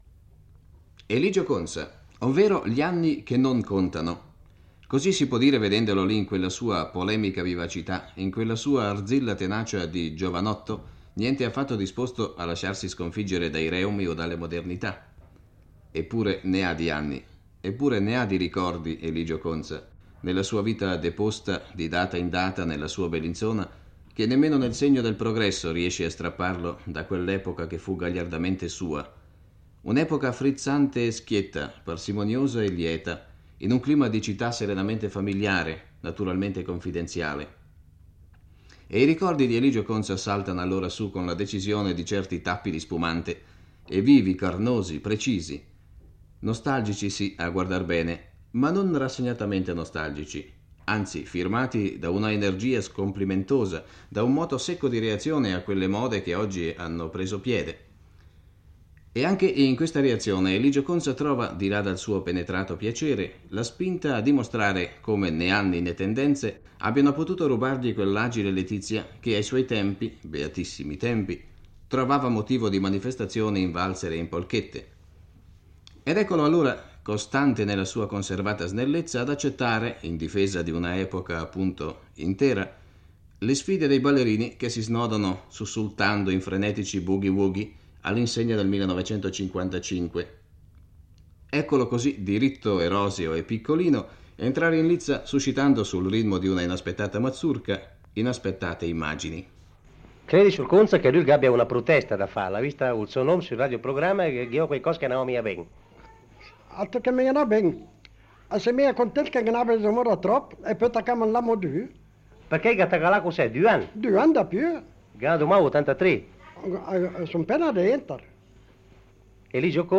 Nell’intervista ricorda i giochi dell’infanzia e un carnevale ormai scomparso, quando ancora si combattevano battaglie d’arance e la satira aveva un ruolo centrale.